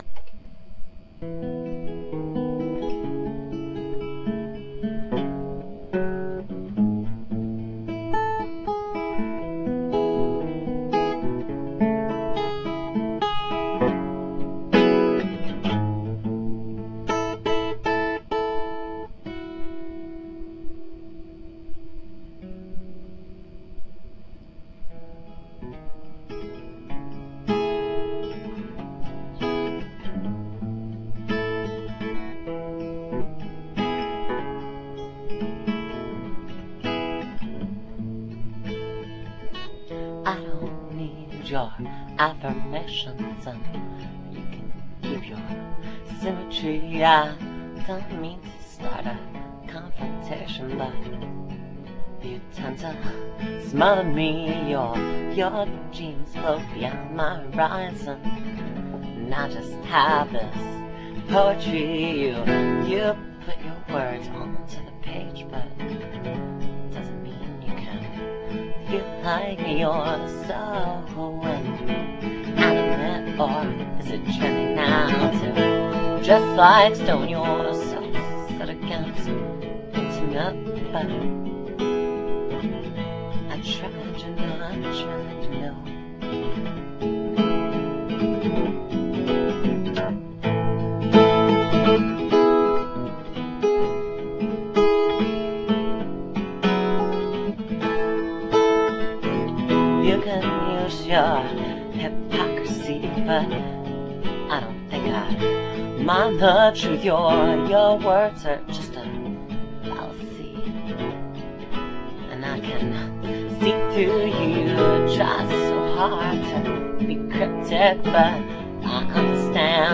lead and backing vocals,
acoustic and rhythm guitar